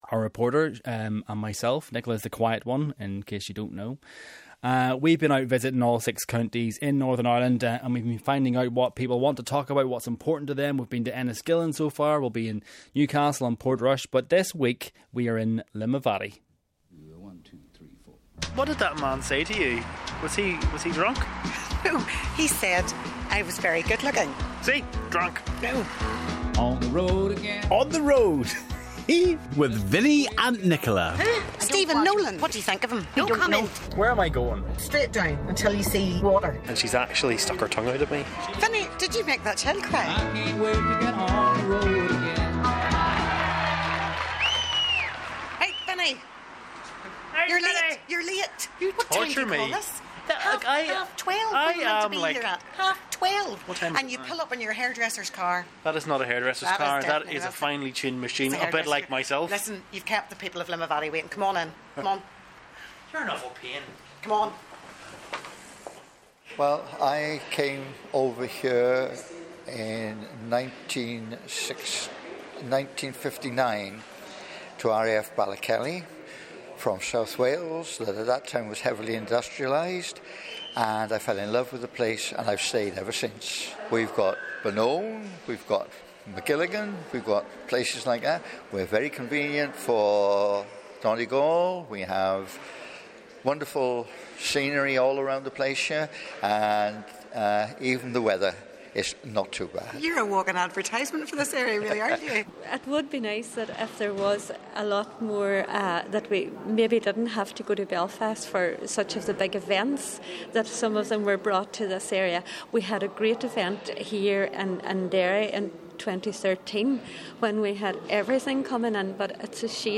They've been finding out what YOU want to talk about - and what issues matter to you. This week they're in Limavady....